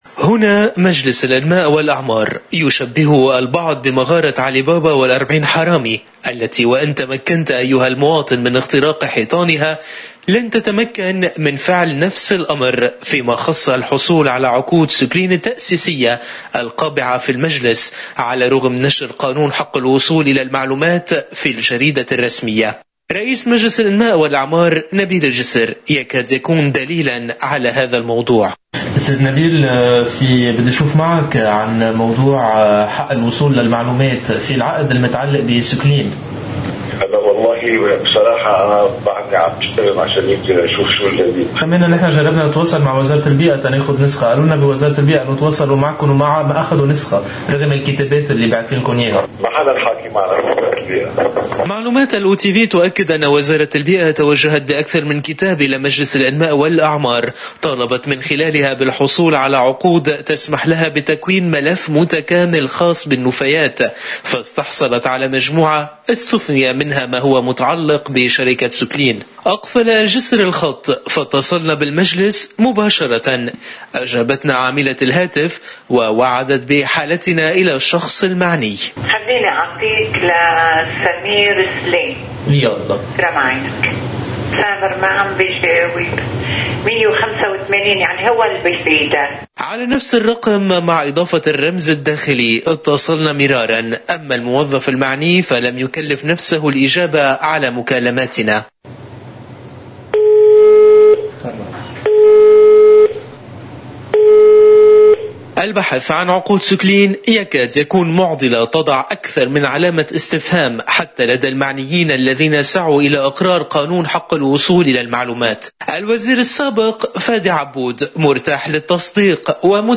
قناة الـ”OTV” تعاود المكالمة مع إضافة الرقم الداخلي، ولكن لا إجابة من الشخص المعنّي..